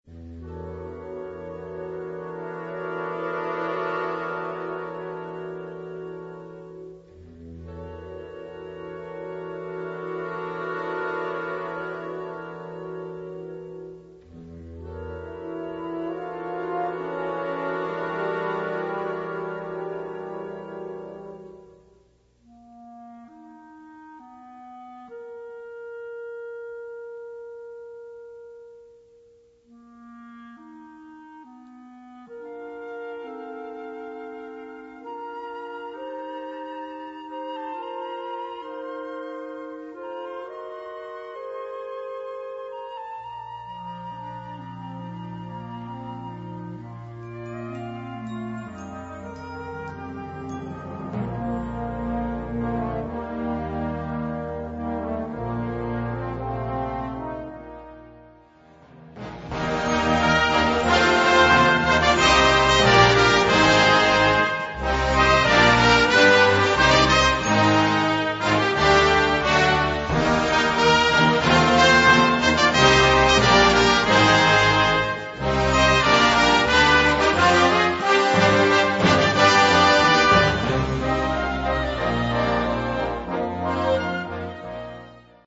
Gattung: Filmmusik
Besetzung: Blasorchester